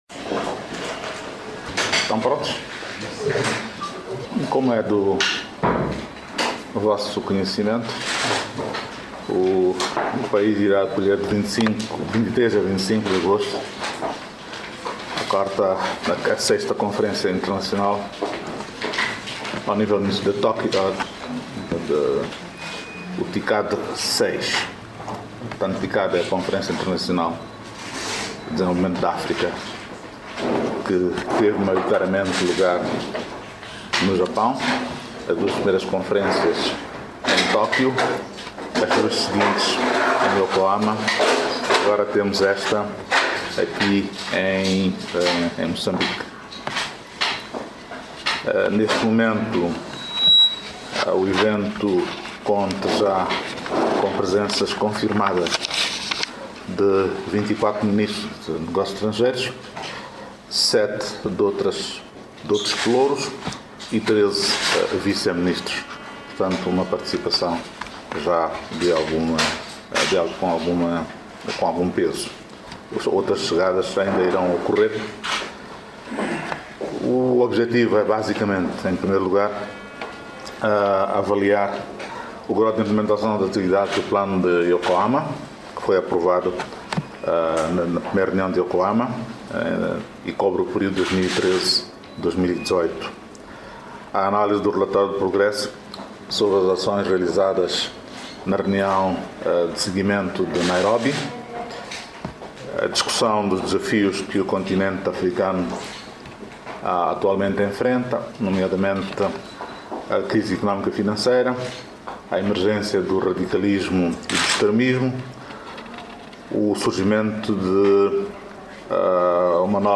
Confira a baixo o áudio das declarações do Ministro dos Negócios Estrangeiros e Cooperação à media.